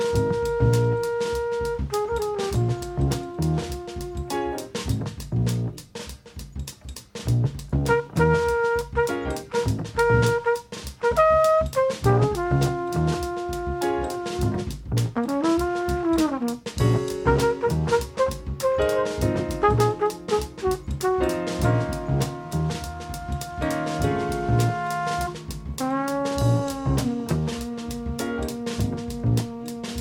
"templateExpression" => "Jazz"